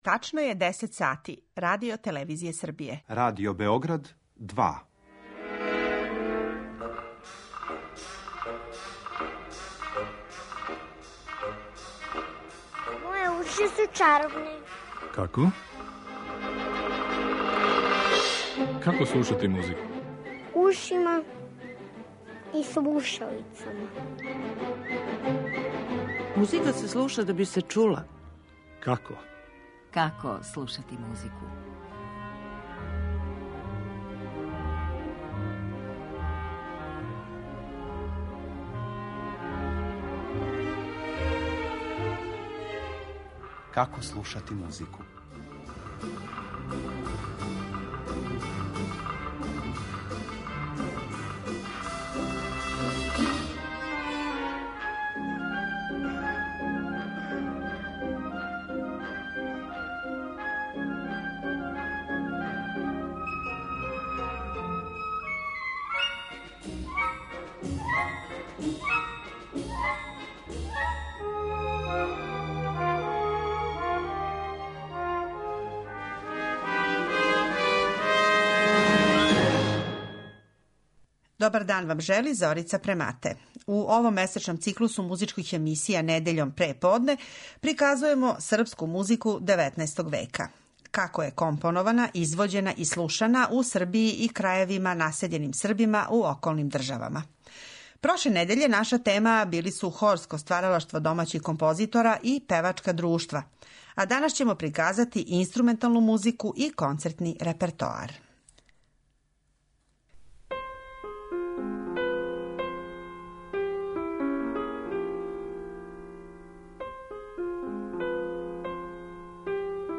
Између осталих, чућете и композиције за клавир Роберта Толингера, Корнелија Станковића и Јована Пачуа, као и прве композиције за инструментални ансамбл настале на тлу Србије пре више од 150 година, а из пера Јосифа Шлезингера.